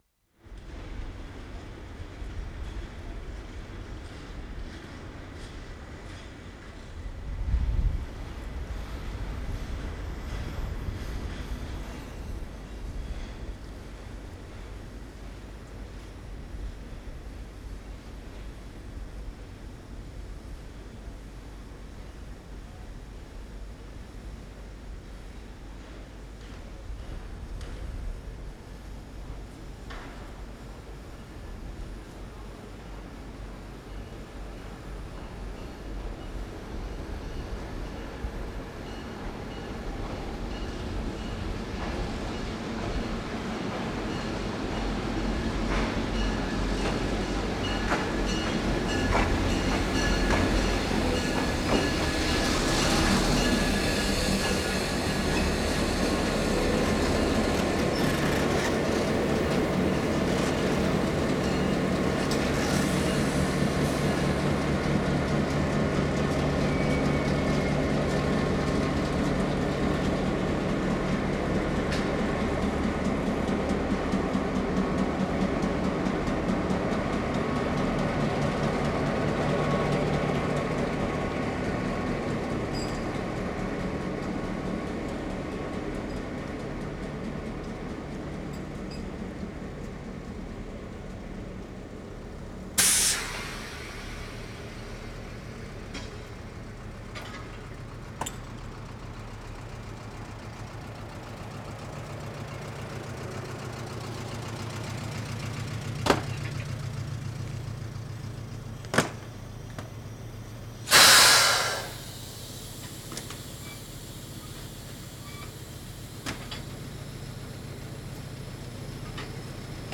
WORLD SOUNDSCAPE PROJECT TAPE LIBRARY
2. Not much happening, no roar of engines, little else to focus on to define this event of train arriving, other than the engine bell.
*0'35" train bell approaching.
0'55" train pulls up quietly.